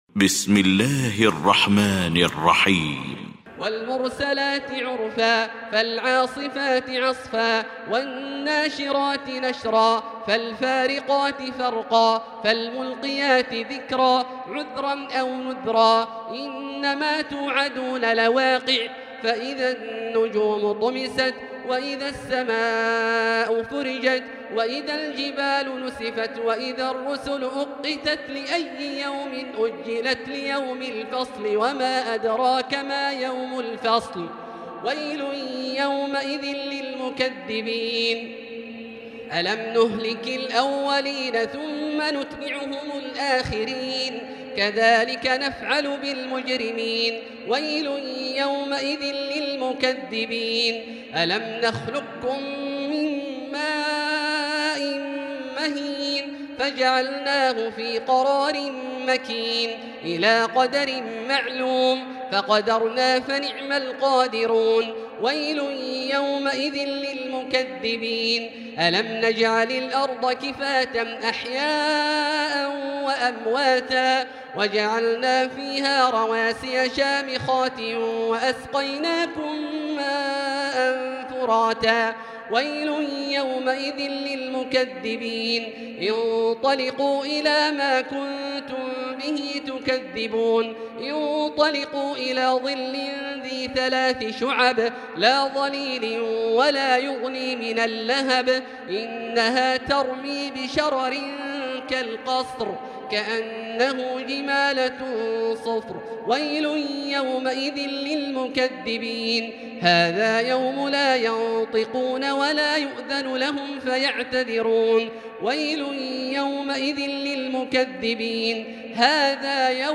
المكان: المسجد الحرام الشيخ: فضيلة الشيخ عبدالله الجهني فضيلة الشيخ عبدالله الجهني المرسلات